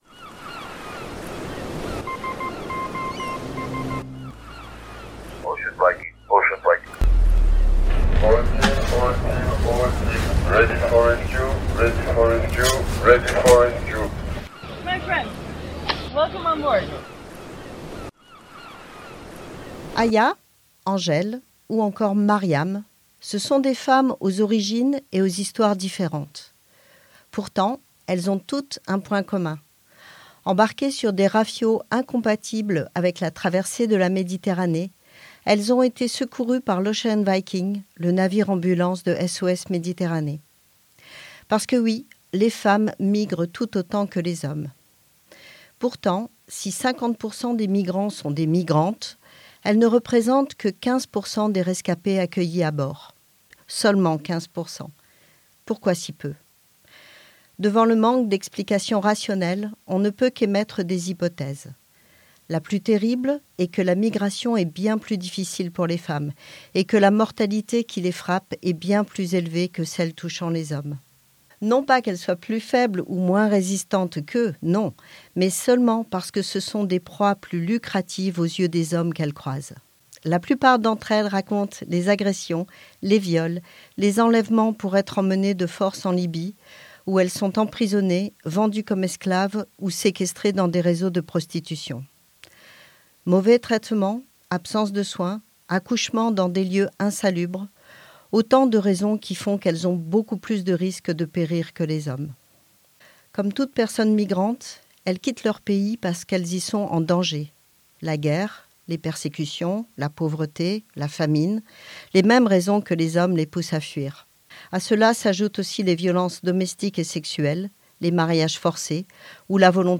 Musique : Ocean Viking, y’a de quoi te faire confiance de femmes rescapées sur l’Ocean Viking